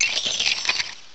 cry_not_klefki.aif